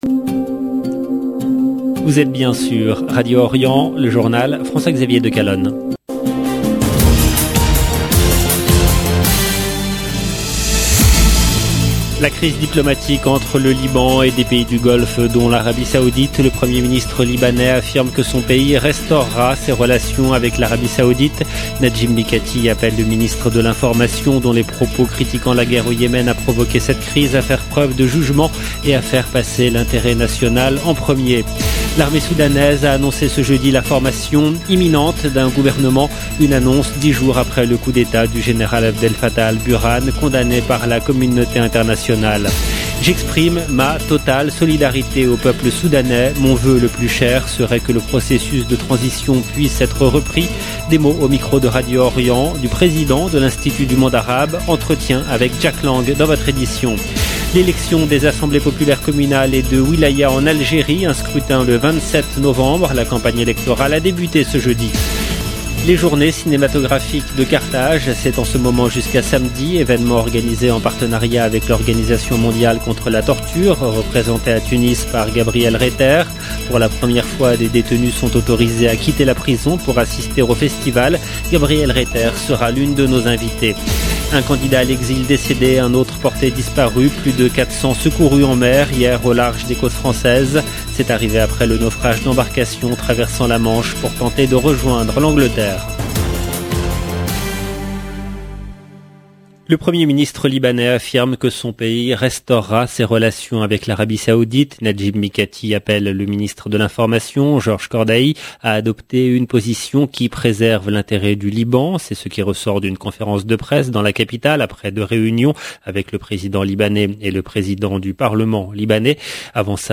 Entretien avec Jack lang dans votre édition.